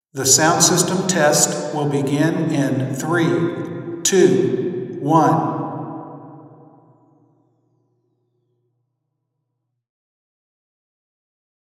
Figure 10 – Measured vs. predicted RIR for the same seat of a large, reverberant auditorium.
↑ Convolution of measured RIR.
RIR_01_321Intro_XYSTEREO.wav